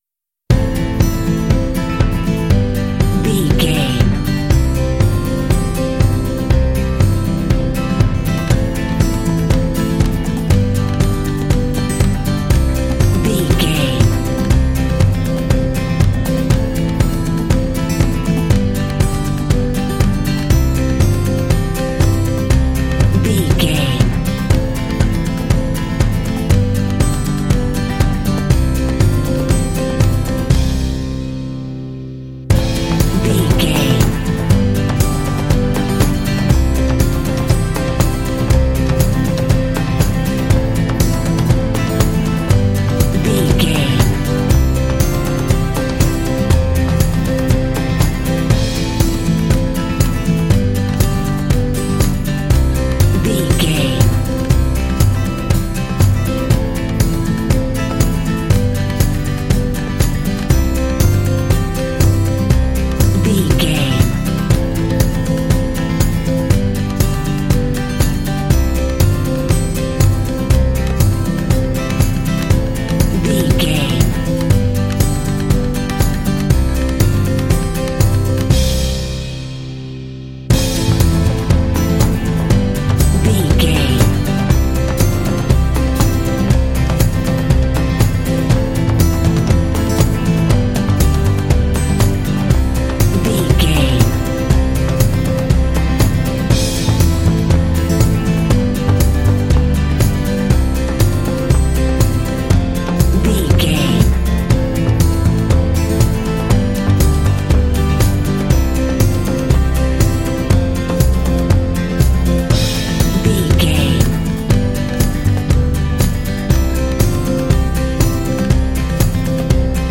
Ionian/Major
inspirational
hopeful
soothing
acoustic guitar
bass guitar
strings
percussion